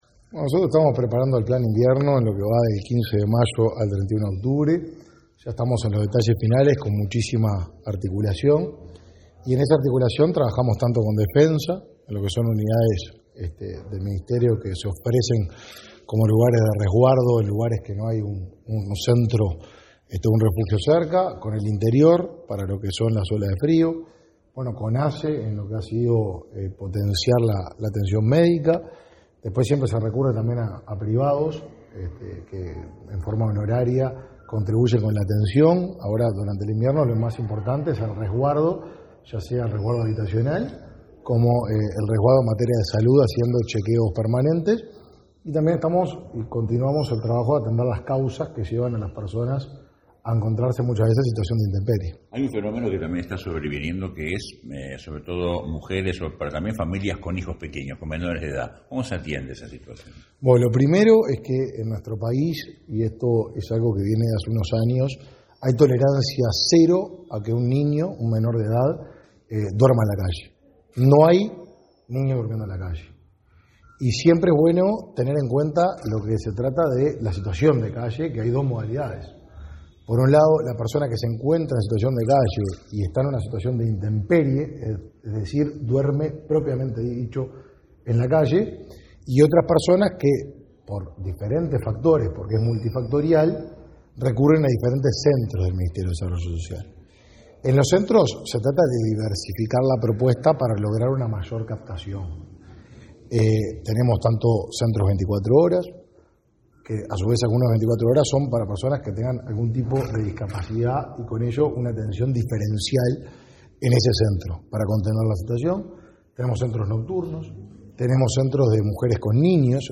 Declaraciones a la prensa del ministro de Desarrollo Social, Martín Lema